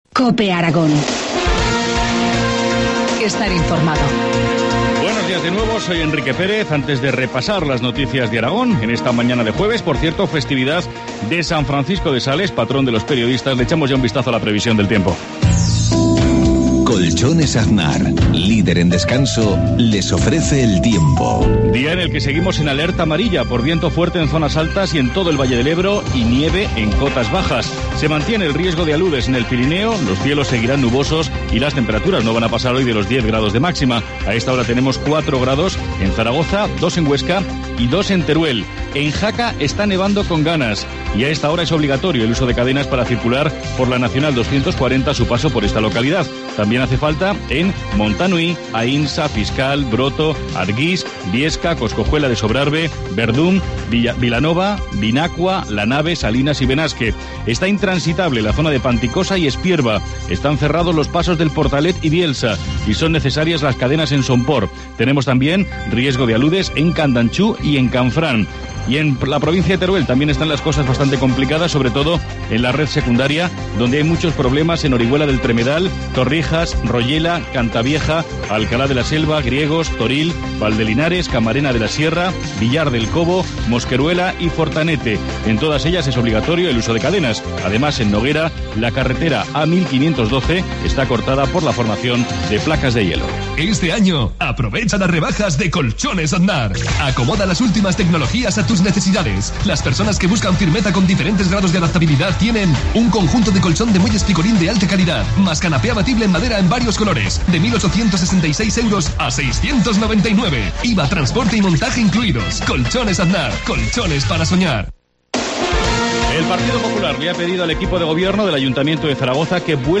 Informativo matinal, jueves 24 de enero, 7.53 horas